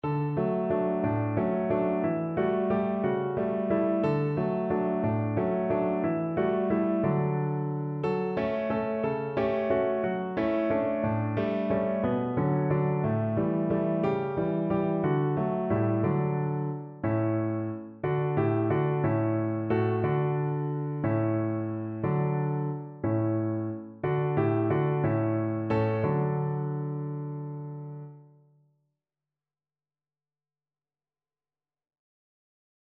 Traditional Trad. Bring a Torch, Jeanette, Isabella (Un flambeau, Jeannette, Isabelle) Piano version
No parts available for this pieces as it is for solo piano.
"Bring a Torch, Jeanette, Isabella" (French: Un flambeau, Jeannette, Isabelle) is a Christmas carol which originated from the Provence region of France in the 16th century.
3/4 (View more 3/4 Music)
D major (Sounding Pitch) (View more D major Music for Piano )
Traditional (View more Traditional Piano Music)
bring_a_torch_PNO.mp3